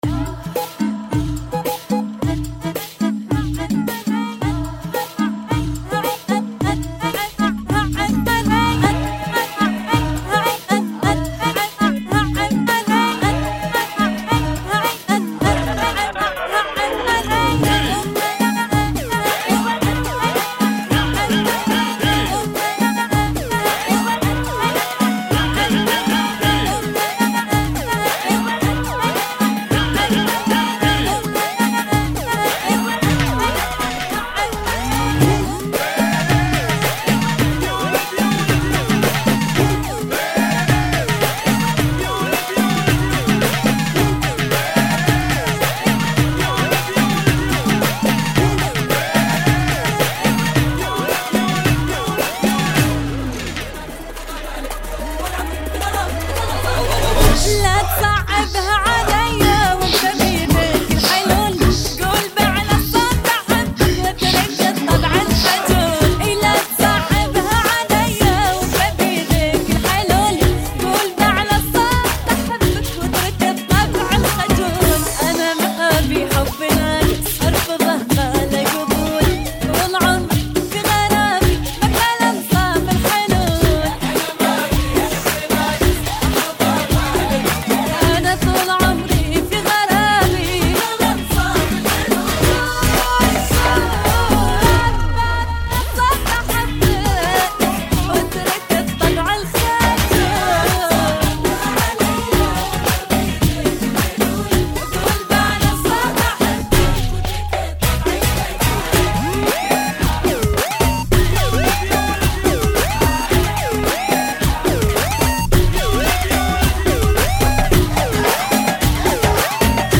[ BPM 109 ]